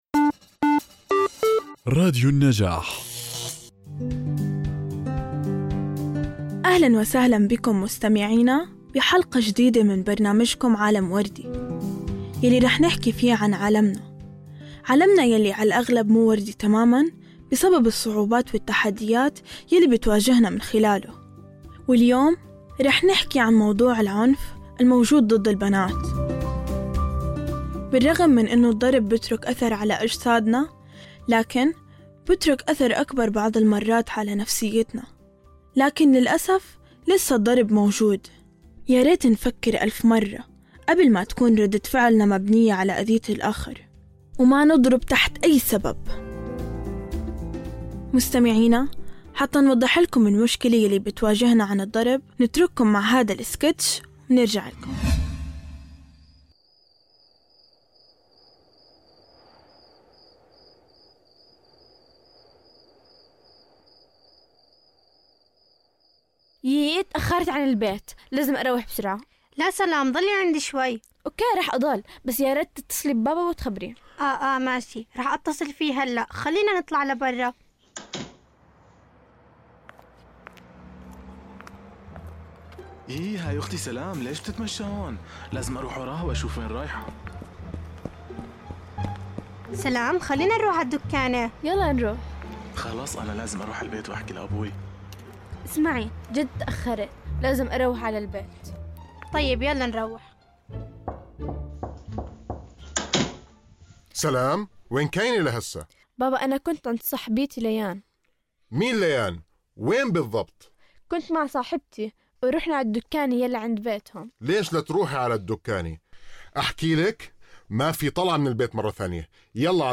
من خلال سكيتش تمثيلي مبتكر ومؤثر، تقدم الحلقة صورة حية للتحديات التي تواجهها البنات بسبب الواقع المؤلم للعنف، يشتمل السكيتش على أحداث وقصص حقيقية تم تجسيدها بطريقة تعكس الواقع الذي يعيشه الكثير من الفتيات والنساء، وتبرز اثر هذا العنف على صعيد حياتهم النفسية والإجتماعية.
بودكاست عالم وردي هو برنامج مميز يقدمه مجموعة من اليافعات المشاركات ضمن مشروع نبادر في مركز حكاية لتنمية المجتمع المدني، يتميز هذا البودكاست بتضمينه سكيتشات تمثيلية درامية تعكس تجارب الفتيات واليافعات في مواجهة قضايا مثل التمييز بين الذكور والإناث، عدم الثقة بالبنات، تأثير السوشل ميديا على حياتهن، العنف ضد البنات والعصبية.